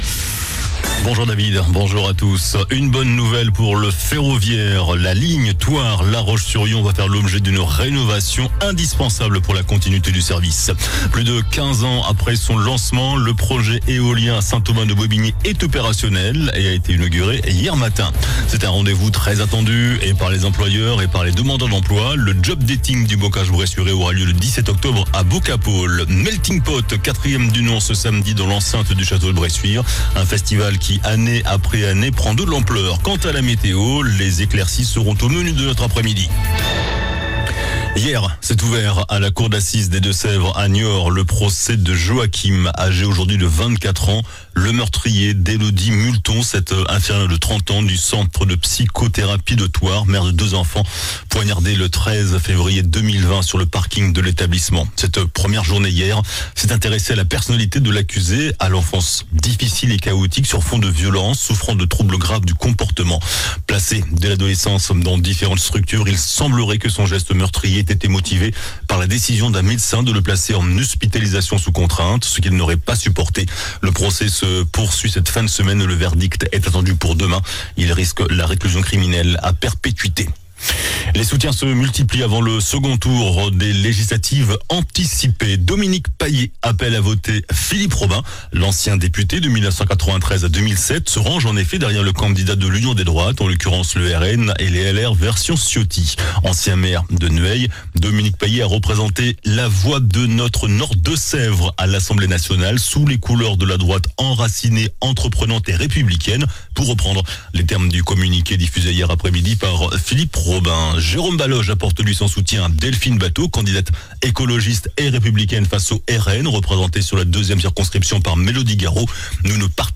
JOURNAL DU JEUDI 04 JUILLET ( MIDI )